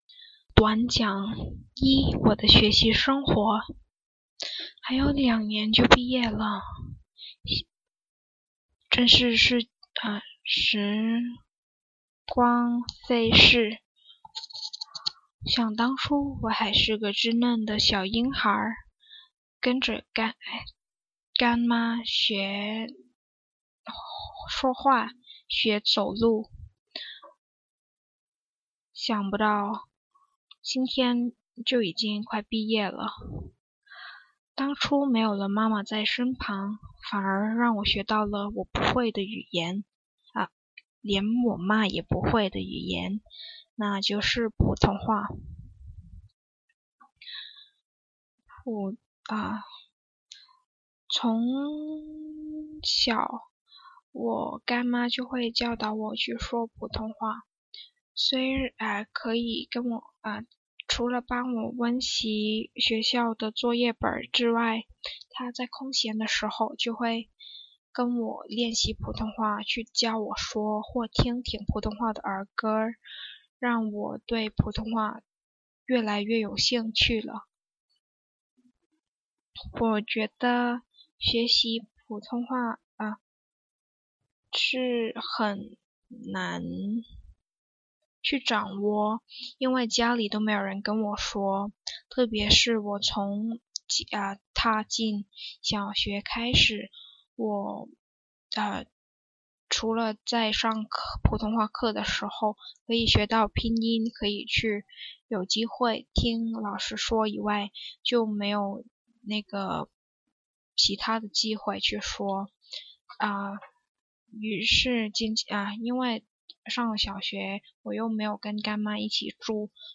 Task 4 Free Speech
Gender : Female
First Language : Cantonese
Second Language : Mandarin